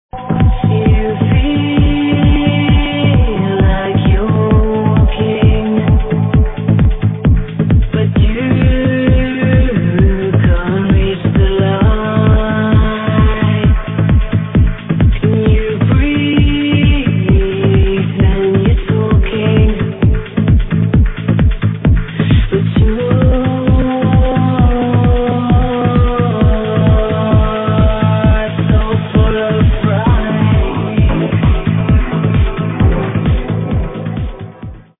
Ripped from id&t radio
Very cool sounding track ripped from Id&t radio.